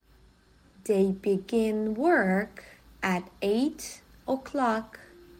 جدول کلمات، جمله ها و معنی آن به همراه تلفظ با سه سرعت مختلف:
تلفظ با سرعت‌های مختلف